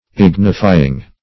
ignifying.mp3